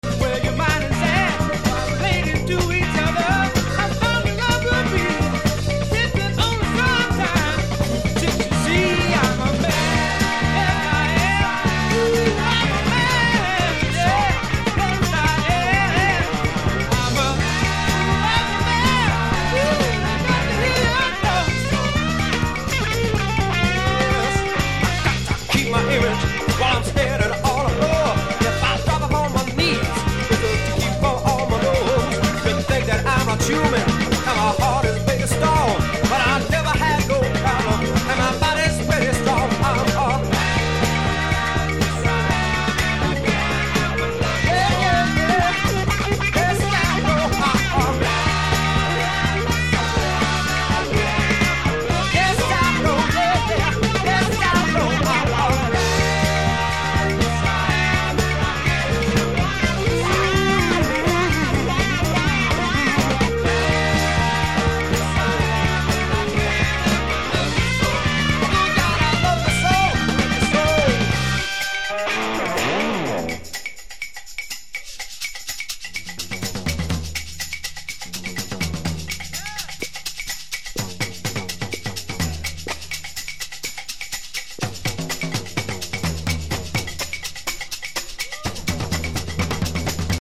in favor of brash beats and raw rhymes
Hip Hop Pop